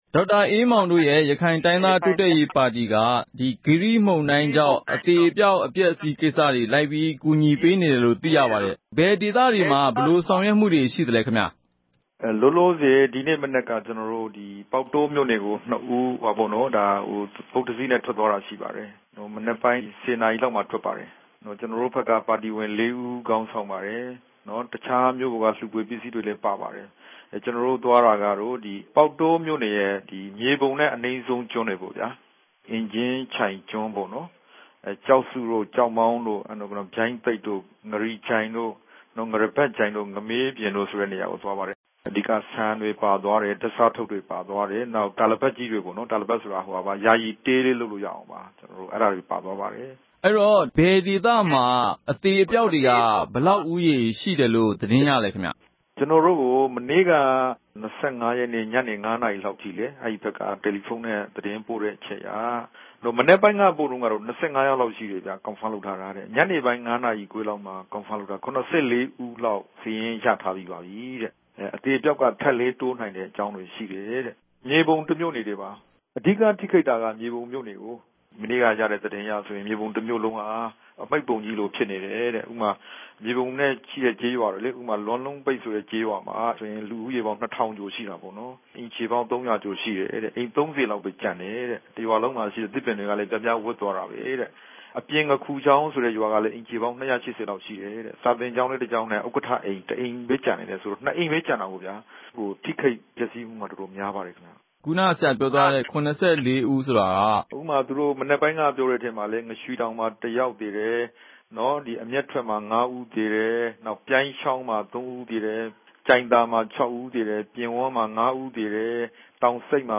အဲဒီကိစ္စနဲ့ ပတ်သက်လို့ ပေါက်တောမြို့ခံ အမျိုးသမီးတဦးက RFA ကို အခုလိုပြောပါတယ်၊ သေမှန်းရှင်မှန်းမသိ ပျောက်နေသူတွေလည်း ရှိပြီး အဲဒီဒေသက ဒုက္ခသည်တွေမှာ စားစရာ နေစရာမရှိ၊ သောက်ရေတောင် မရှိပဲ ဒုက္ခရောက်နေကြောင်း ပြောပြသွားပါတယ်။